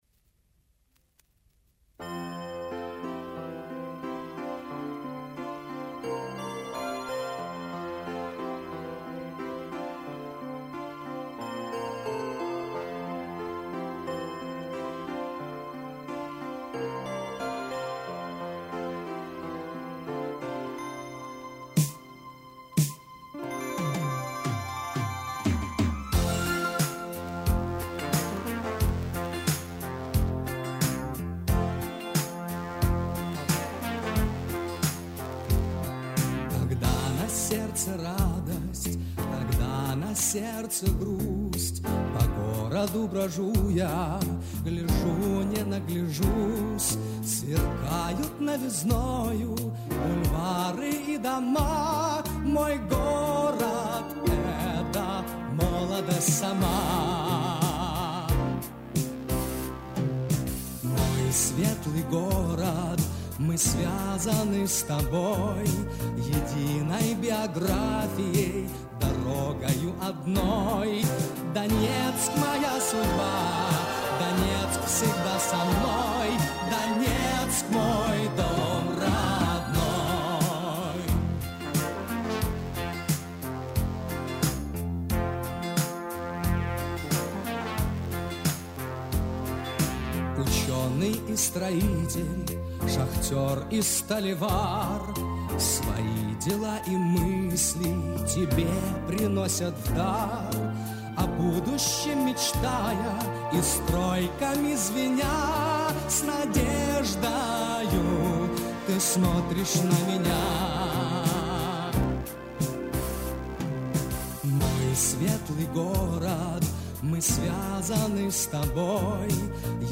Солисты: